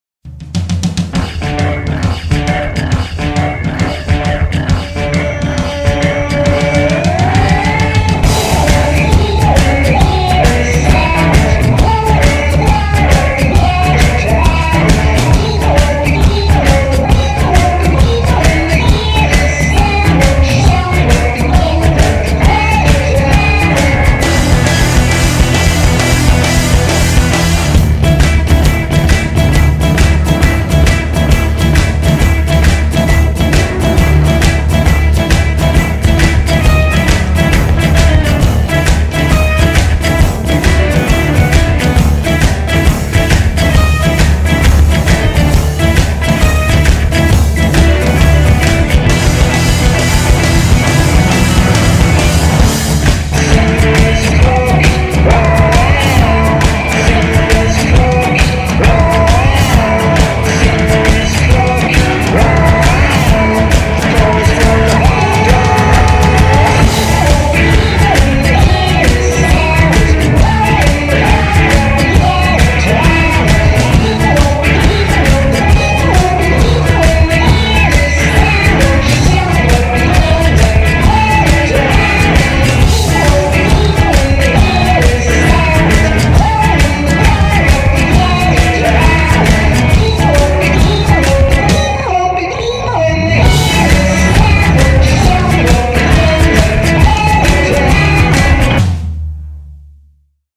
BPM135
indie rock jam
featuring a distinct swing rhythm.